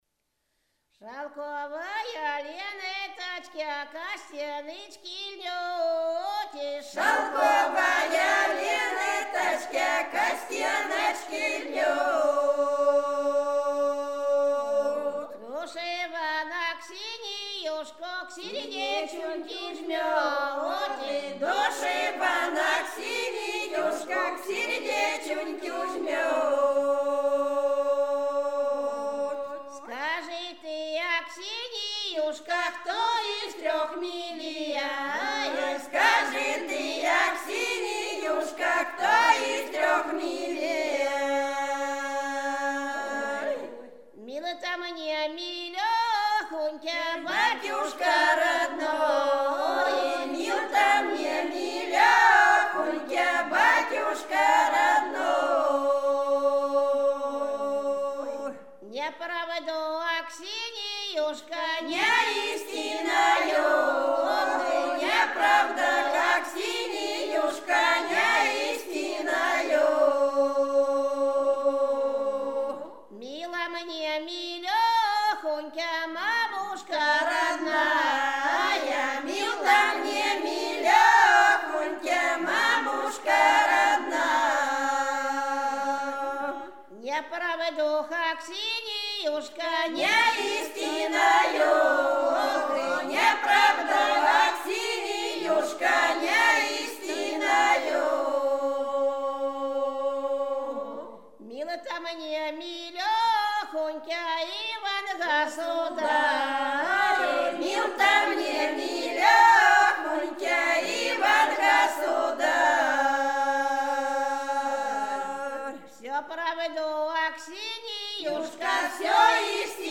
Рязань Секирино «Шалковая ленточки», свадебная.